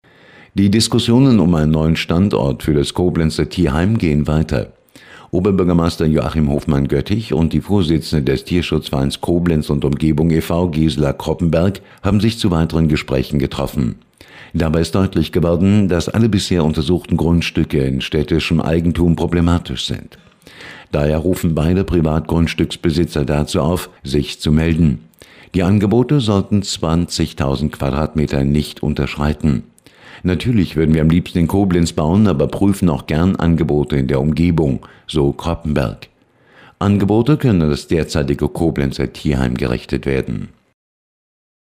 Antenne Koblenz 98,0, Nachrichtensendung am 27.01.2011 (Dauer 00:42 Minuten)